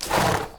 sounds_horse_snort_02.ogg